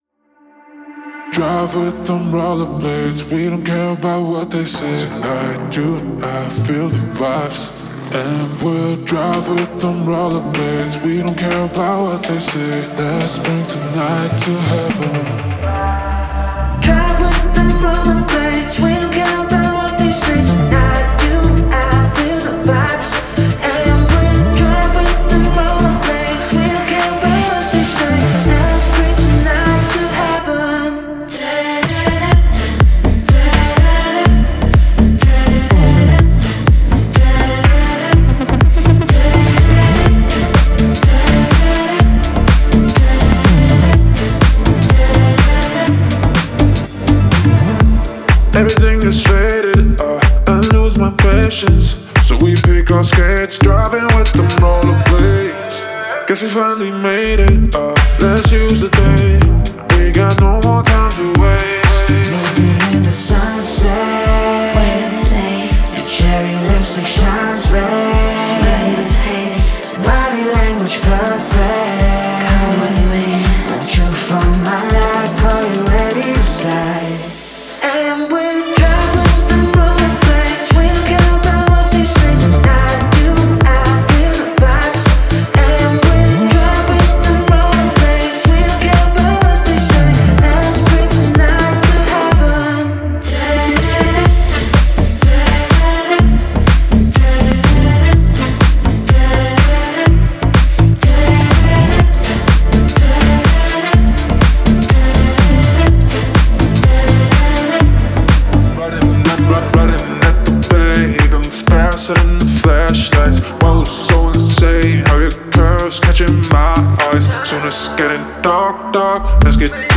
это энергичная и жизнерадостная песня в жанре инди-поп